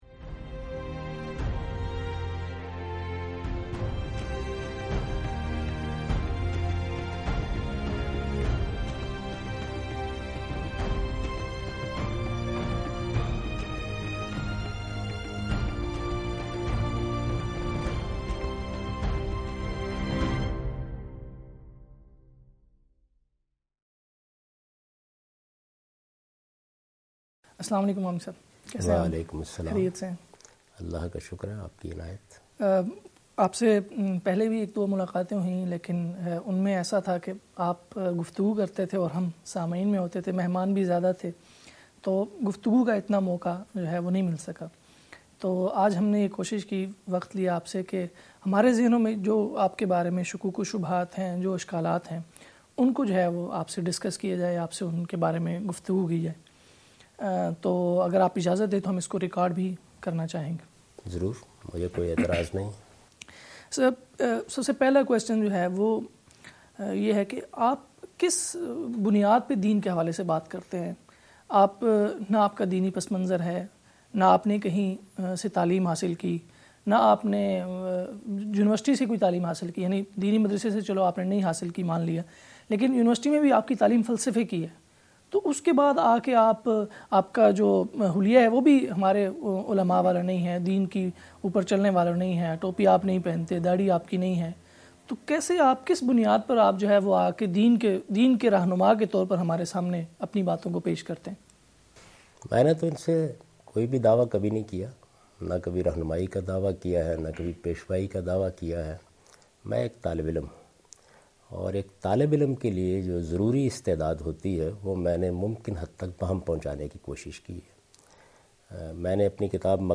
Javed Ahmad Ghamidi responds to some harsh questions from Ulema (Religious scholars).